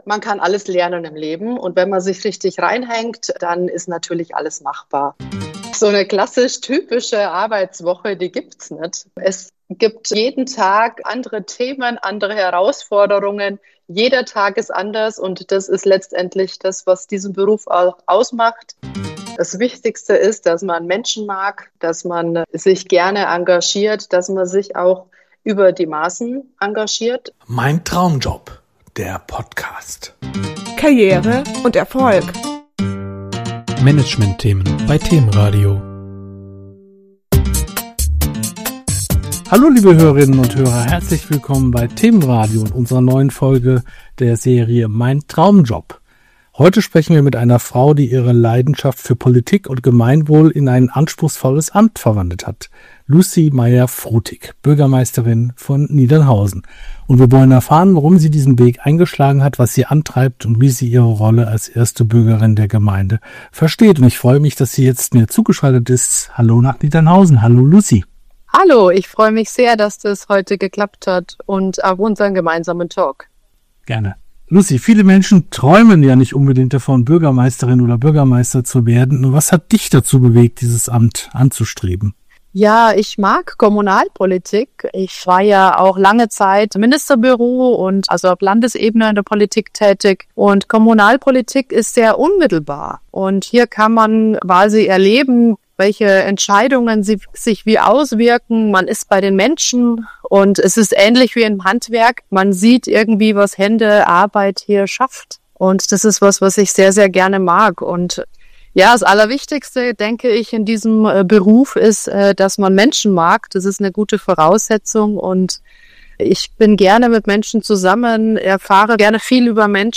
Traumjob – Bürgermeisterin Lucie Maier-Frutig im Gespräch
Ein offenes und persönliches Gespräch über Verantwortung, Nähe zu den Menschen und den ganz besonderen Reiz eines Bürgermeisteramts.